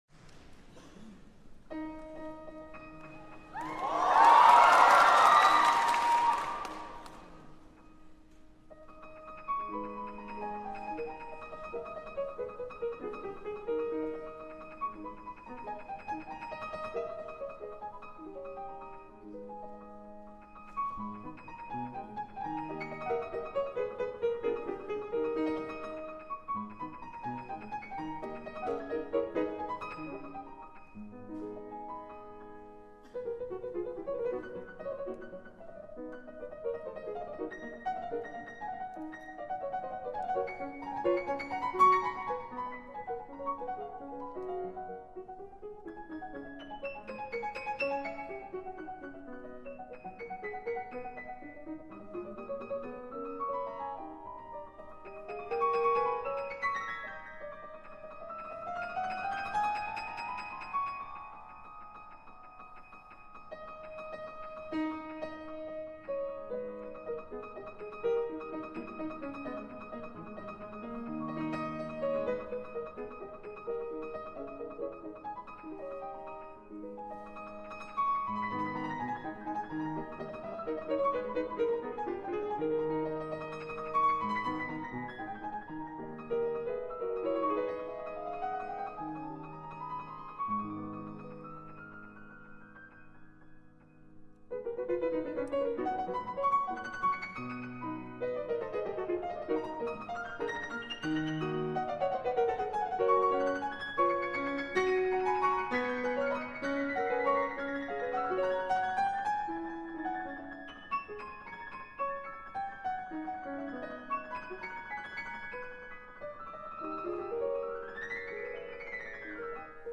• سبک: Romantic (رمانتیک)
• ساز اصلی: پیانو
• پر از پرش‌های دیوانه‌کننده بین اکتاوها
• ملودی ظریف و زنگ‌مانند (مثل صدای زنگوله)
• گلیساندو
• ضربات نرم در نت‌های بالای پیانو